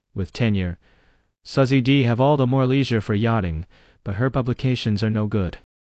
multilingual multilingual-tts text-to-speech voice-cloning